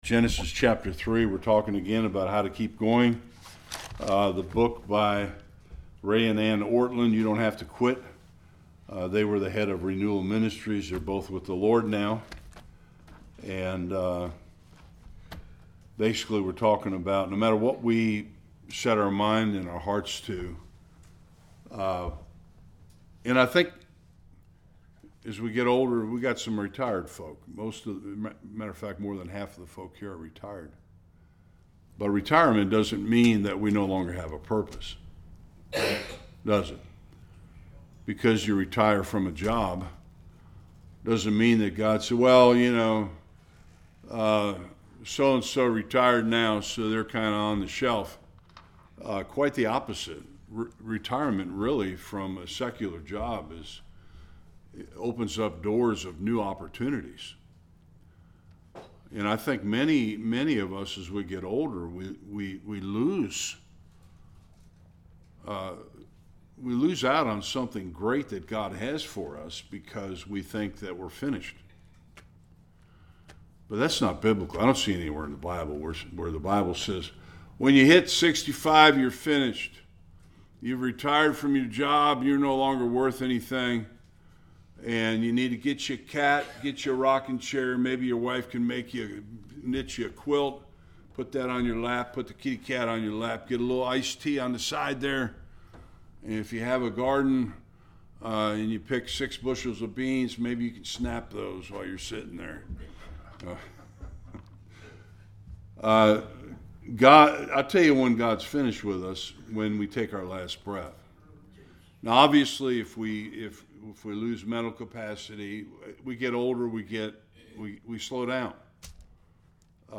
Various Passages Service Type: Bible Study The world and the Church are full of quitters.